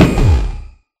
Sound / Minecraft / mob / enderdragon / hit2.ogg
hit2.ogg